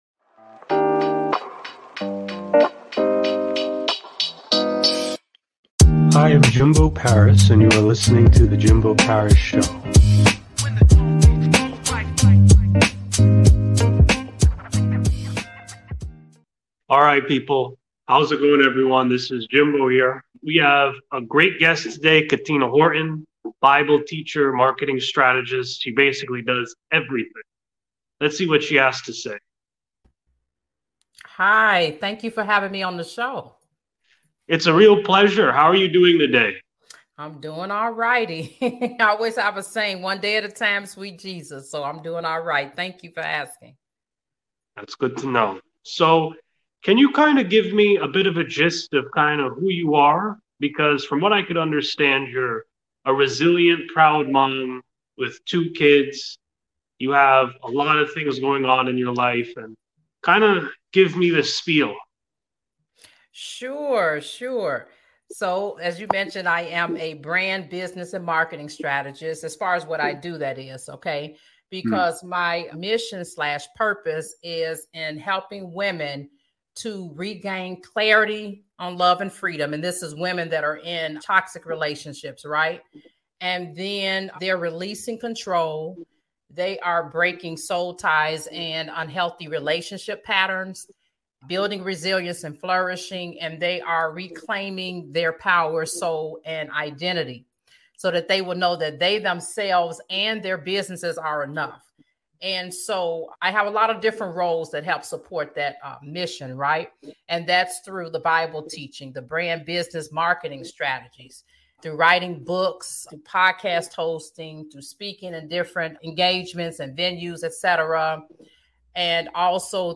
Live Interview